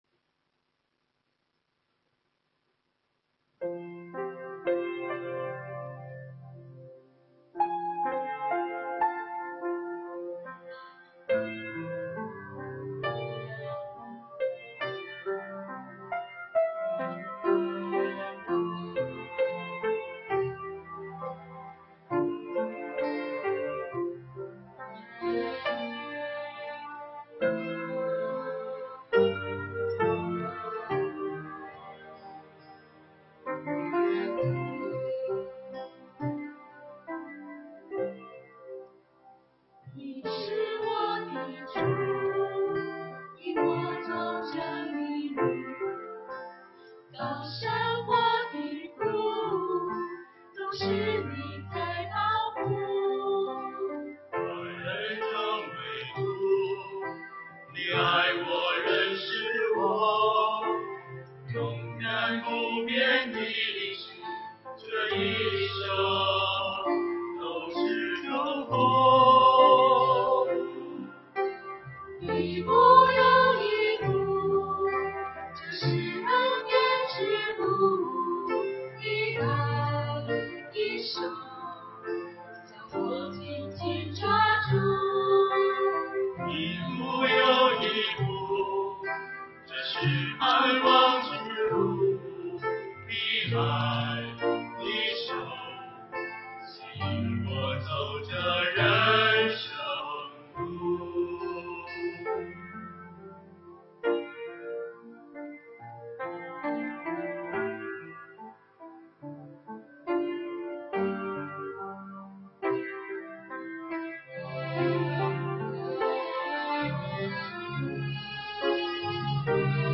团契名称: 清泉诗班 新闻分类: 诗班献诗 音频: 下载证道音频 (如果无法下载请右键点击链接选择"另存为") 视频: 下载此视频 (如果无法下载请右键点击链接选择"另存为")